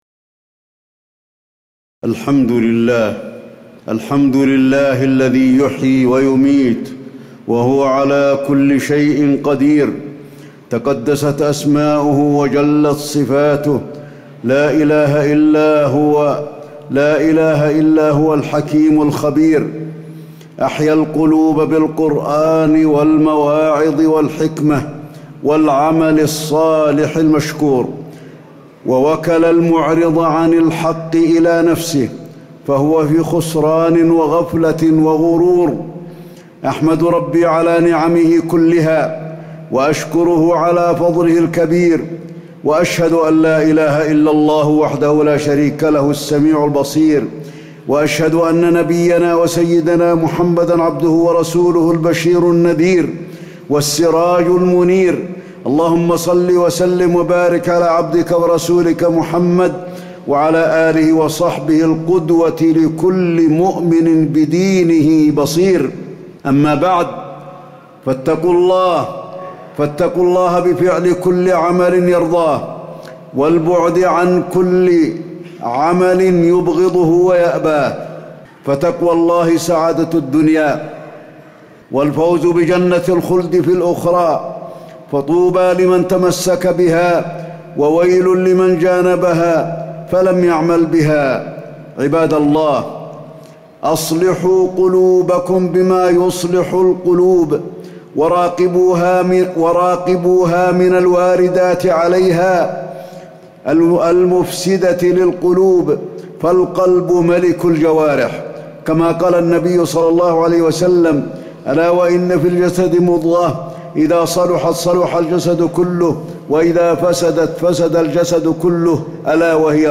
تاريخ النشر ٩ شعبان ١٤٣٨ هـ المكان: المسجد النبوي الشيخ: فضيلة الشيخ د. علي بن عبدالرحمن الحذيفي فضيلة الشيخ د. علي بن عبدالرحمن الحذيفي الغفلة